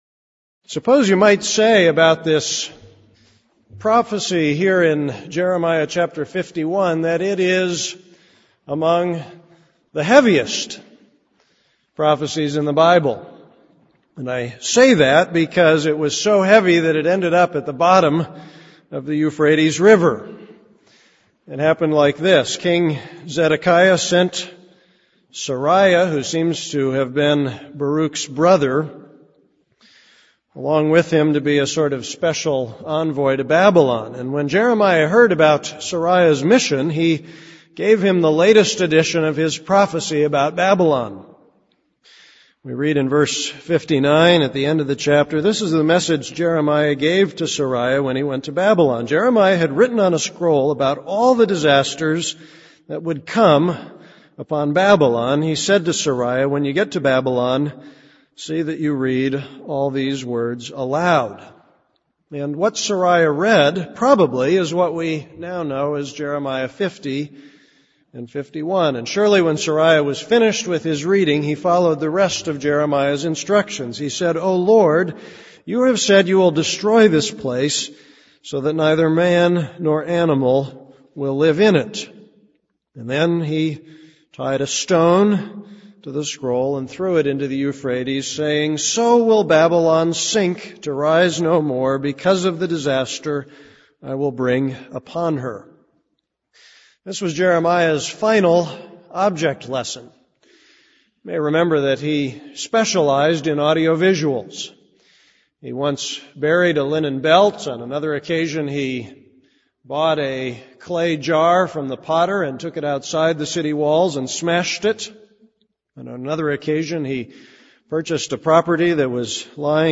This is a sermon on Jeremiah 51:61-64.